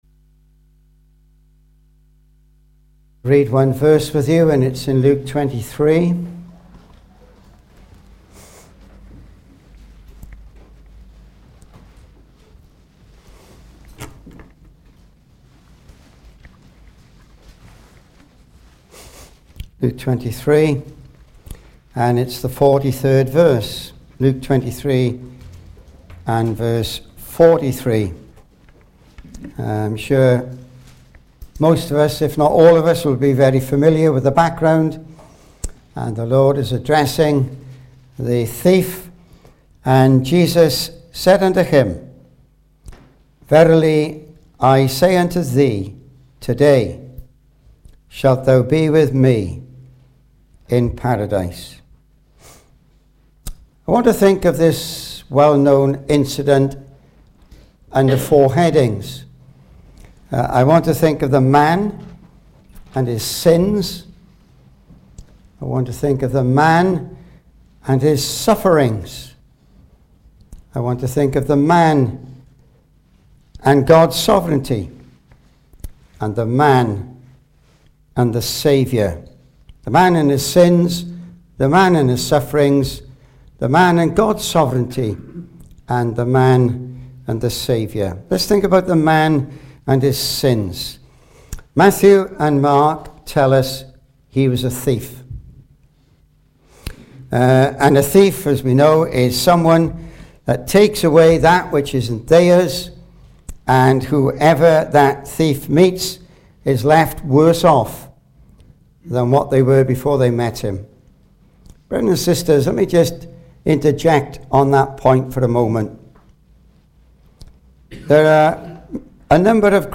Service Type: Ministry